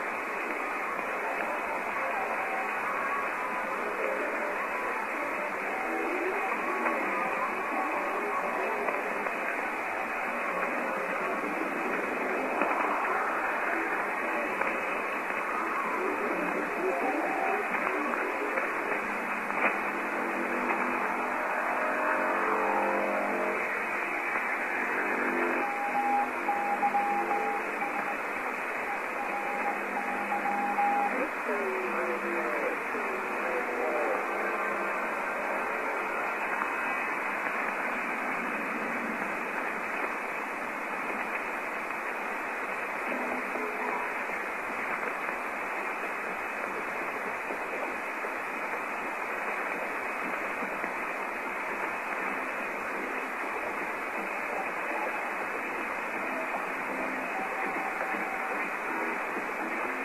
(kHz) Size (Kbytes) Rec.Sts. Comment 07/05/07 04:04 6,200 941�@ poor �@ �� �@->00730":ID(women)-> �@�M�����ア�B �R�O�b��ɏ����̐��łh�c���������܂��B�����̃f�[�^�x�[�X������ƁA6220kHz �ƂȂ��Ă��܂��B